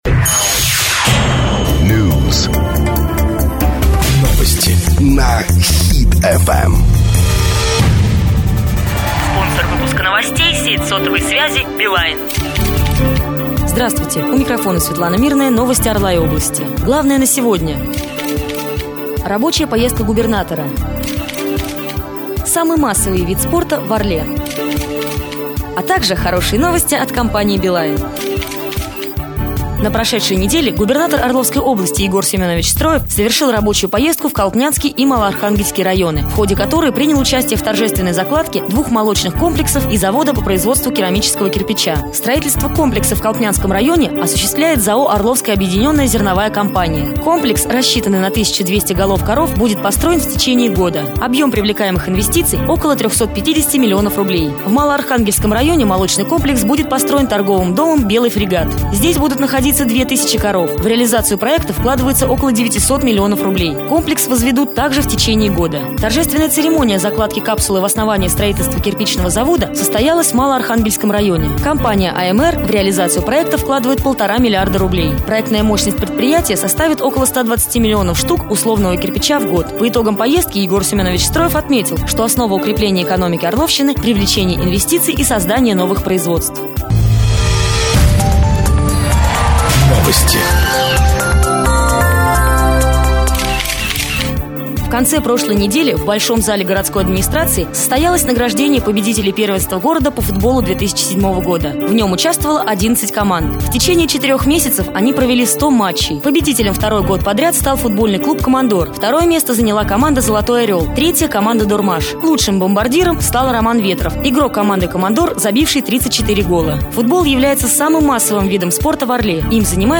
Ведение программы: Программа ведется в деловом строгом стиле.
В начале и в конце программы звучит рекламный ролик спонсора.